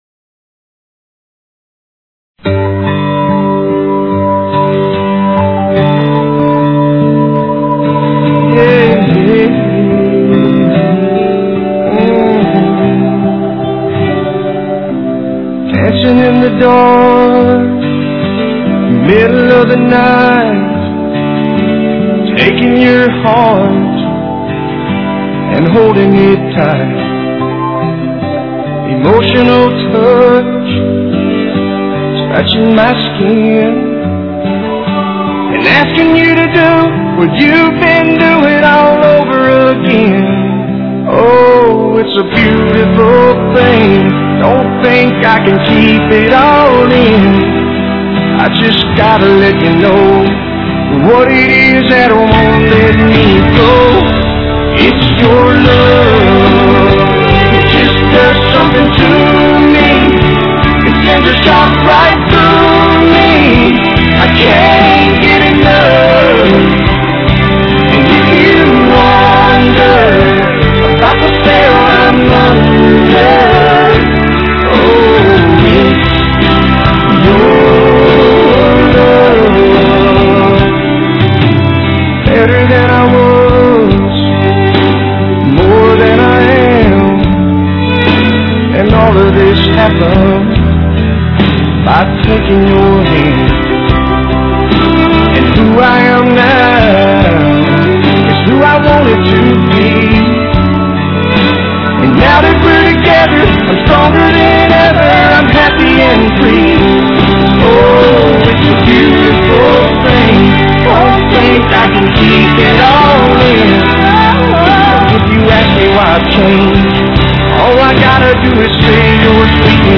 These are some of our favorite slow dance songs.